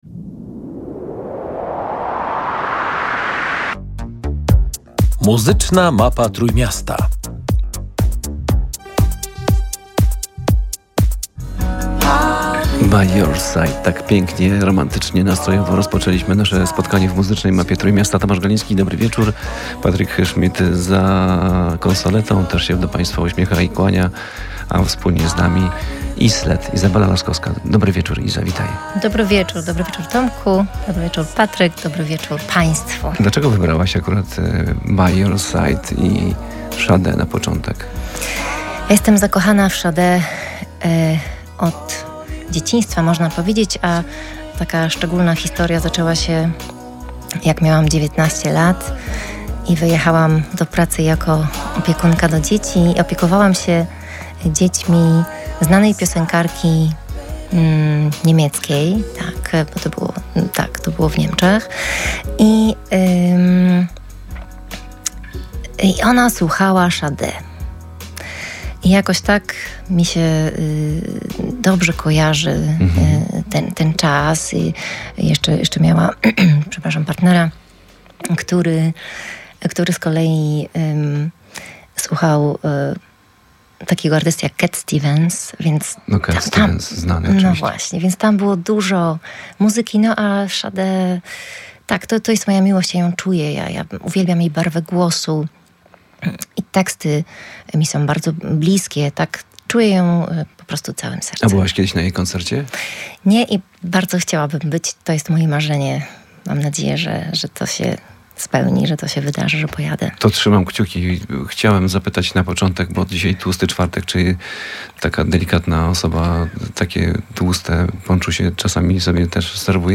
W rozmowie zdradziła, jak wyglądała jej praca resocjalizacyjna – wspólne pisanie tekstów i nagrywanie utworów z osadzonymi – oraz w jaki sposób te przeżycia wpłynęły na jej obecną działalność artystyczną. Artystka pracuje nad nową płytą. Podzieliła się też doświadczeniami związanymi ze szczególnie trudnym dla artystów okresem pandemii.